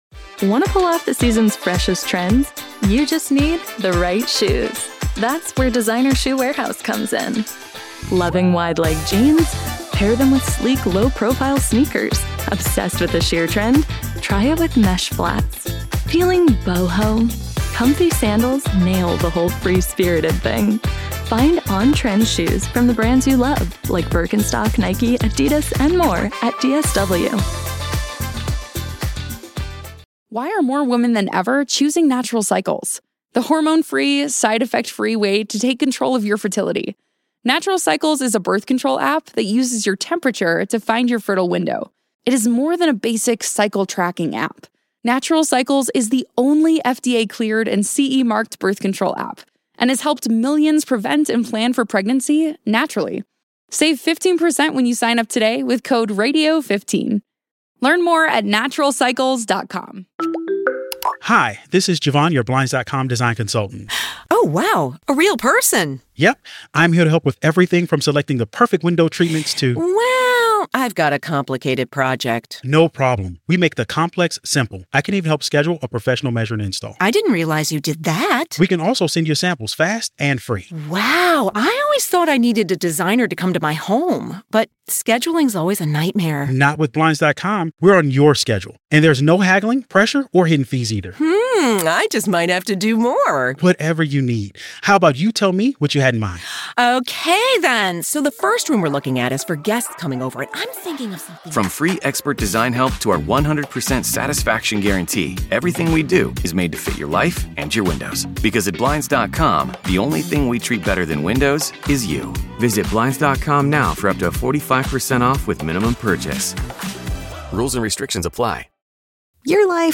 Best of WGR Interviews: August 18 - August 22